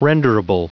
Prononciation du mot renderable en anglais (fichier audio)
Prononciation du mot : renderable